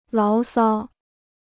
牢骚 (láo sāo): discontent; complaint.